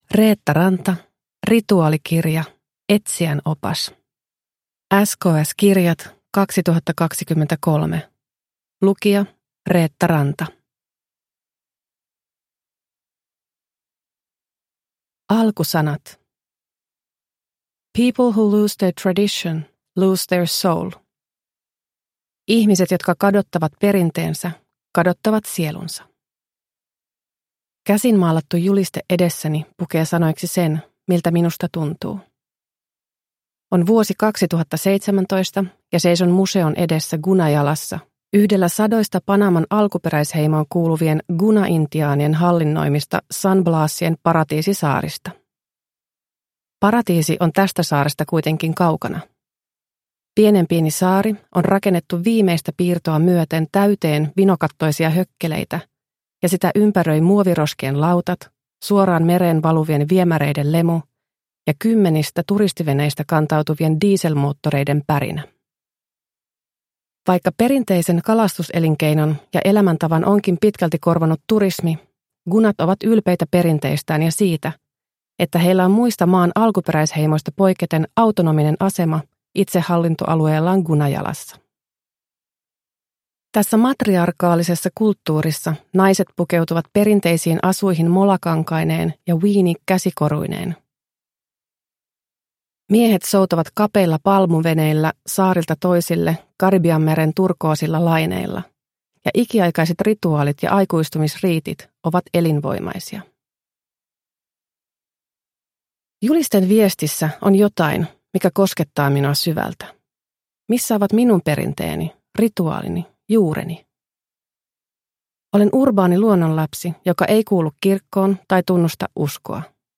Rituaalikirja – Ljudbok – Laddas ner